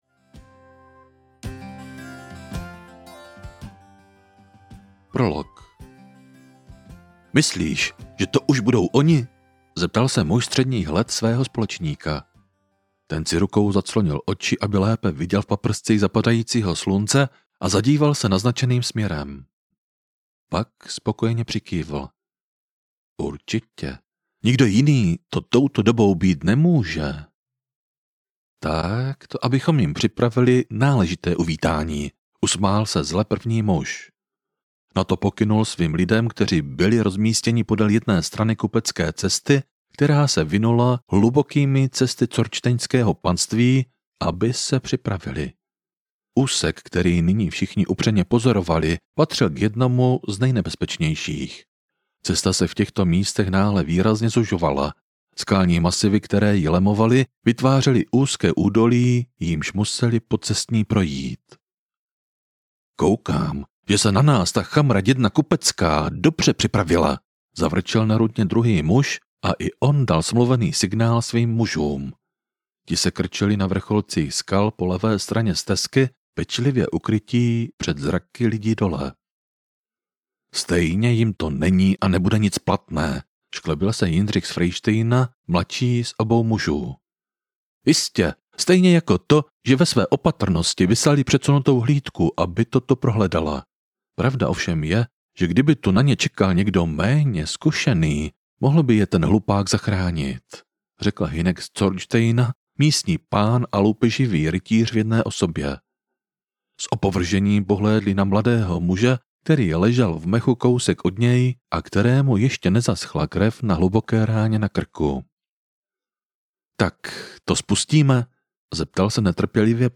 Démoni minulosti audiokniha
Ukázka z knihy